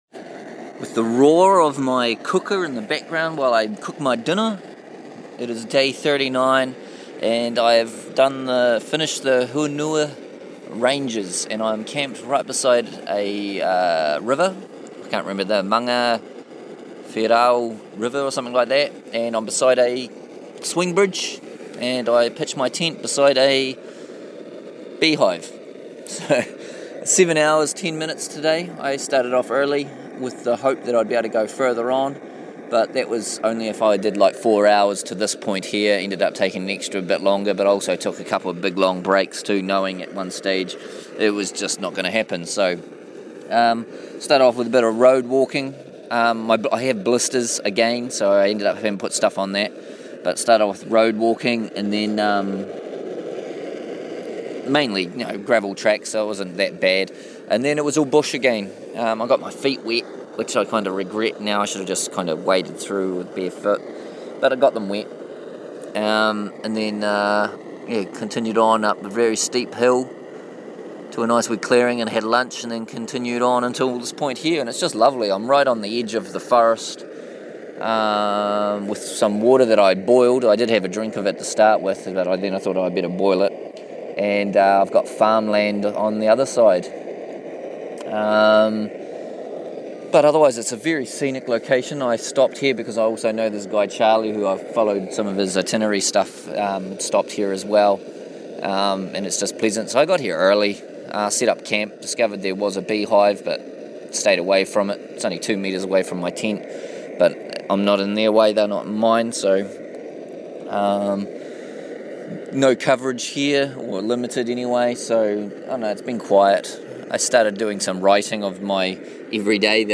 Day 39 7h10m to the edge of the Hunua Ranges track where my camp is beside a river and swingbridge. Plus an amazing amount of insect life that is biting me or could sting me.